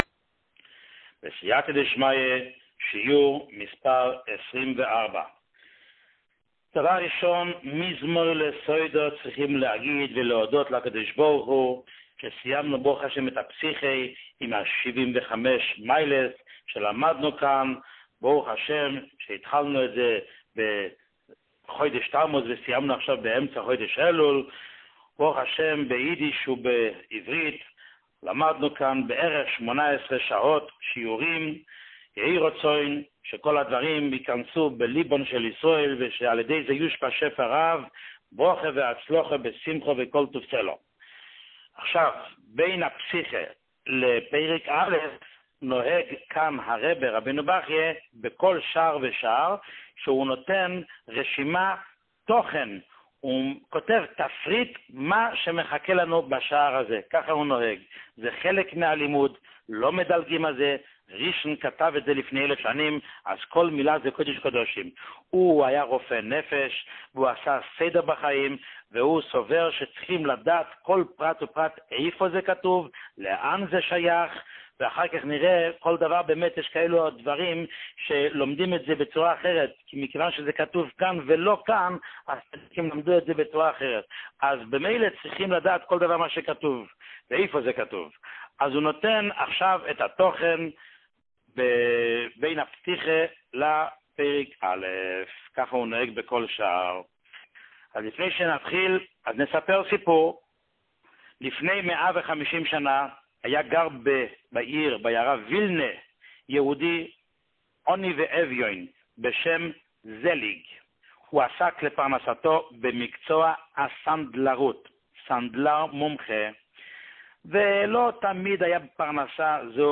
שיעורים מיוחדים
שיעור 24